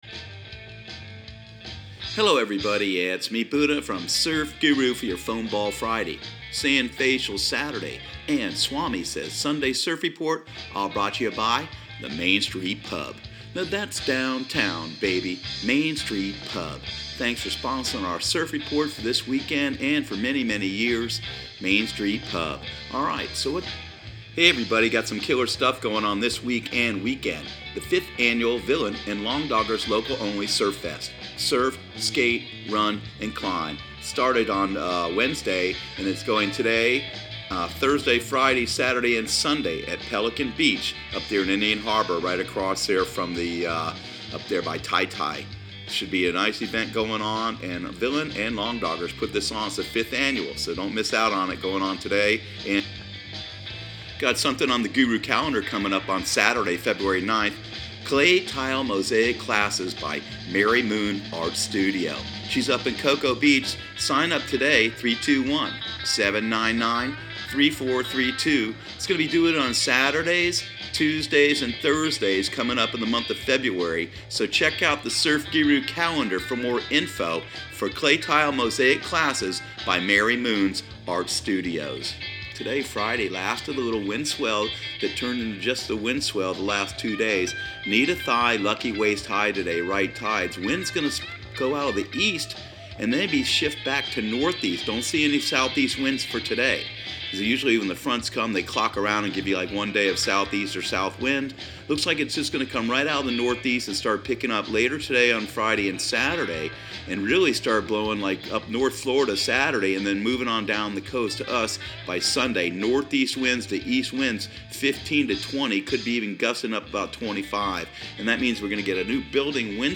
Surf Guru Surf Report and Forecast 02/08/2019 Audio surf report and surf forecast on February 08 for Central Florida and the Southeast.